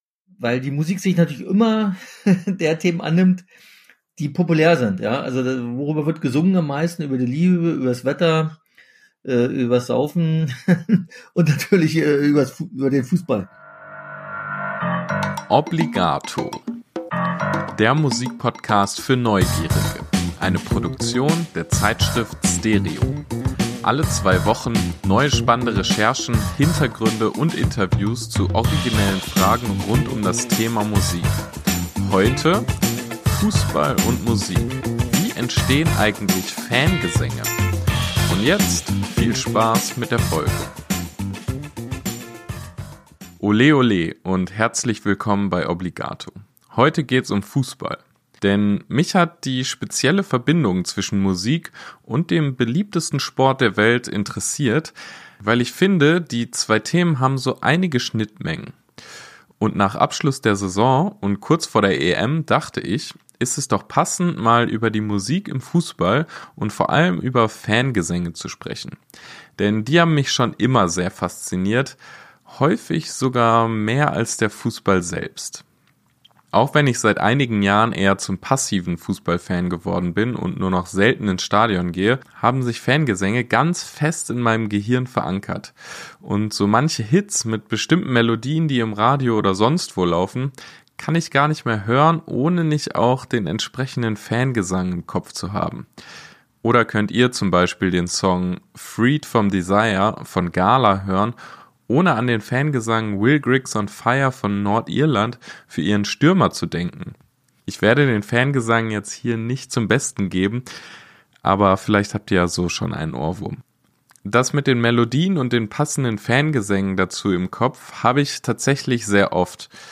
In der 19. Episode von Obligato sind dazu zwei Gäste dabei, die schon seit vielen Jahrzehnten ins Stadion gehen.